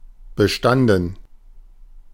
Ääntäminen
Ääntäminen Tuntematon aksentti: IPA: /bəˈʃtandən/ IPA: /bəˈʃtandn̩/ Haettu sana löytyi näillä lähdekielillä: saksa Käännöksiä ei löytynyt valitulle kohdekielelle.